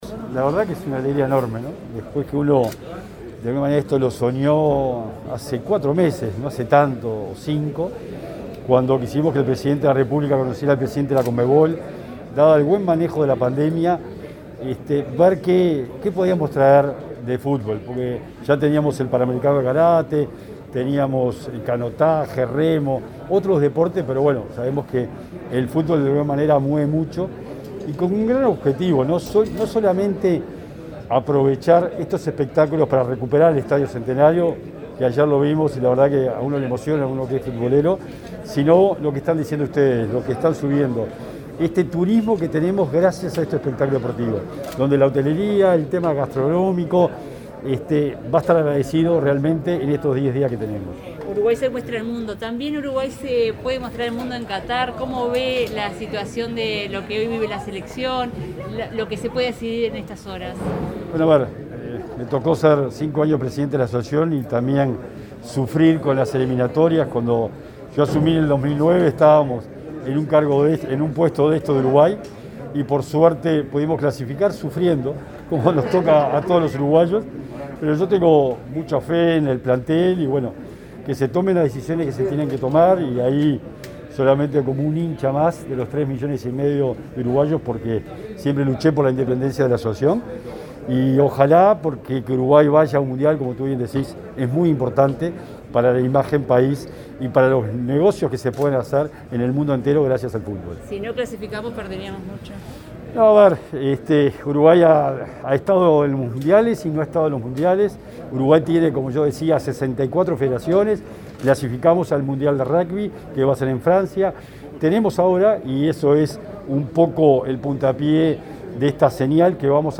Declaraciones a la prensa del secretario nacional del Deporte, Sebastián Bauzá
El secretario nacional del Deporte, Sebastián Bauzá, participó este viernes 10 en la Torre de las Telecomunicaciones en del lanzamiento de la primera